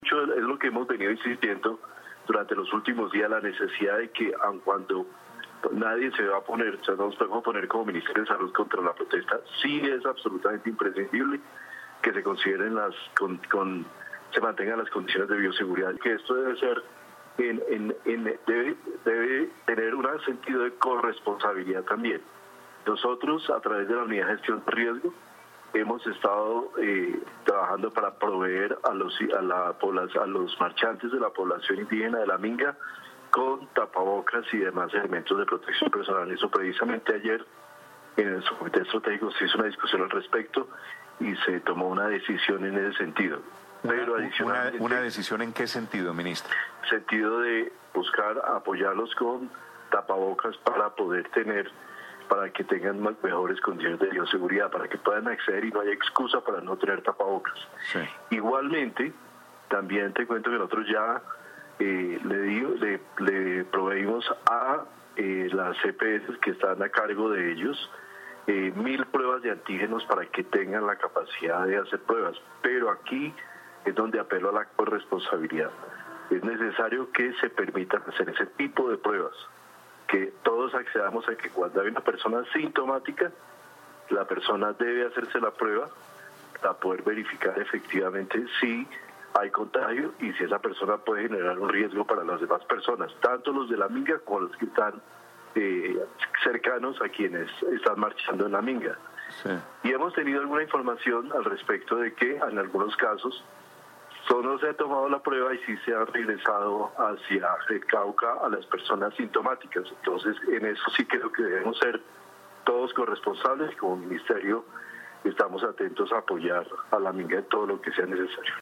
Audio del ministro de Salud y Protección Social, Fernando Ruiz Gómez Bogotá D.C., 20 de octubre de 2020.